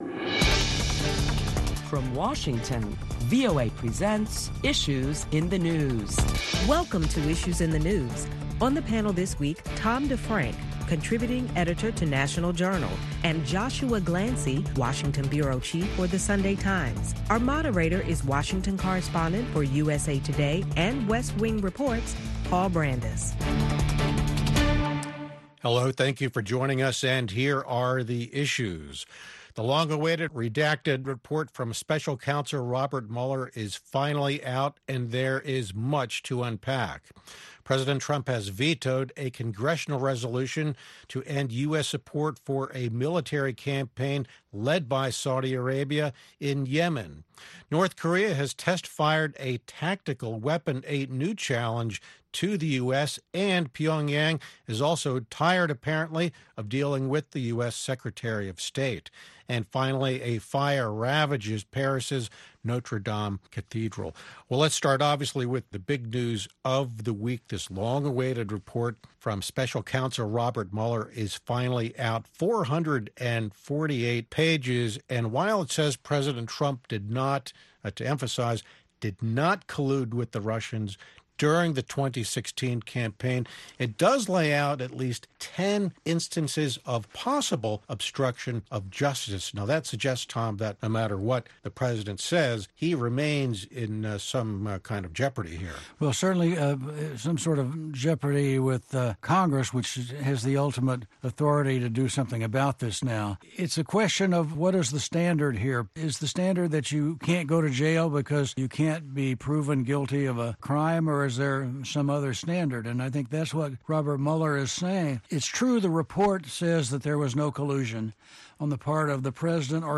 A panel of prominent Washington journalists deliberate the past week's headlines including the release of the redacted Mueller Report, President Trump’s second veto and North Korea test firing tactical weapons for the first time in months.